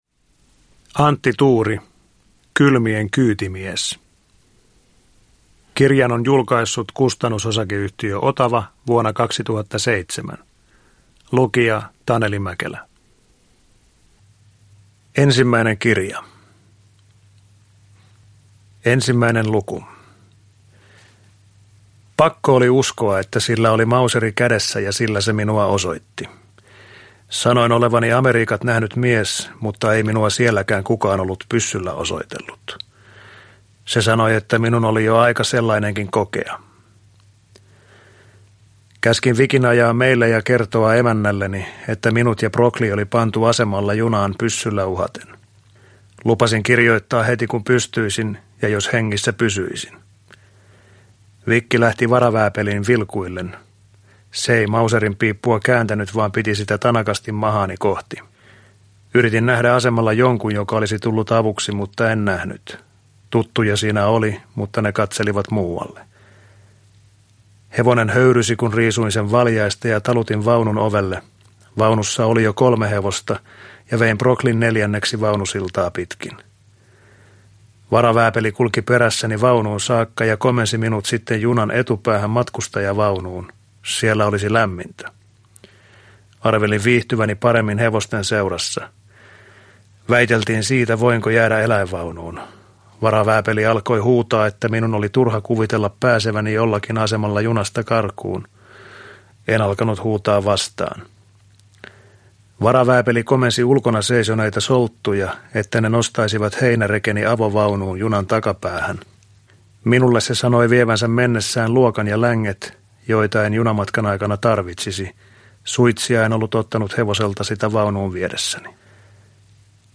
Kylmien kyytimies – Ljudbok – Laddas ner
Uppläsare: Taneli Mäkelä